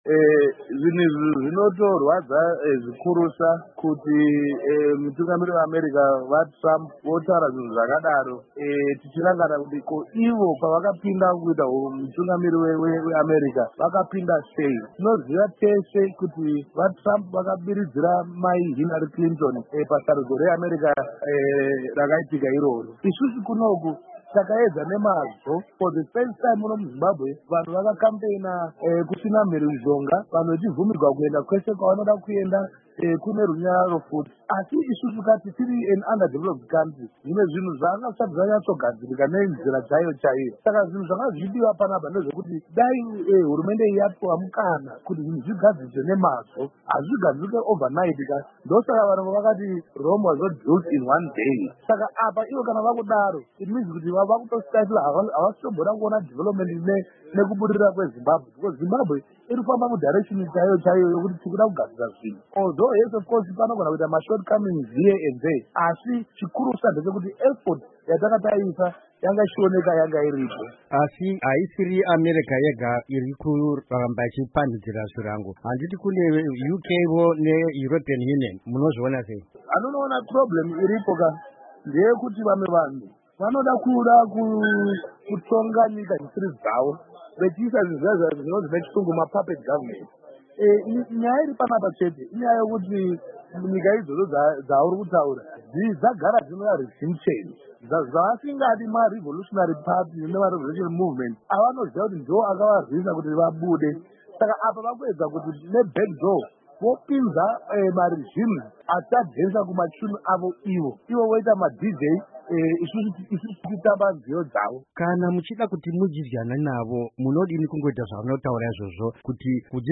Hurukuro naVaJoseph Tshuma